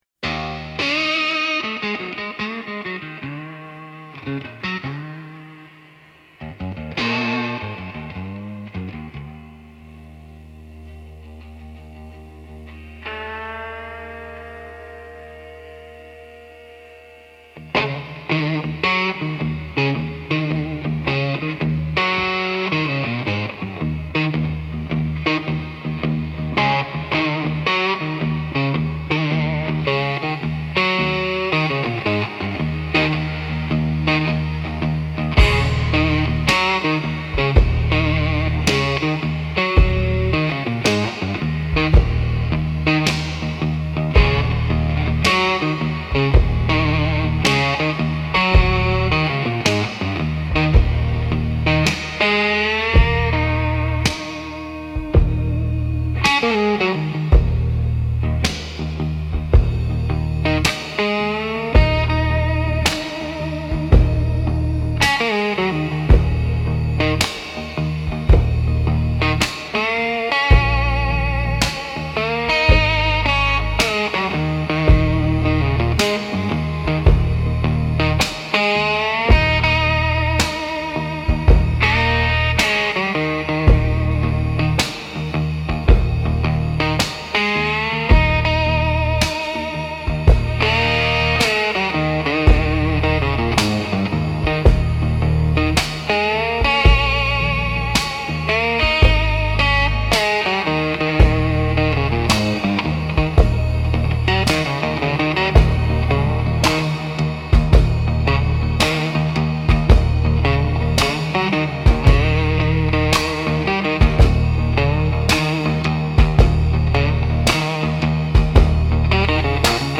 Instrumental - Gravel Road Beatdown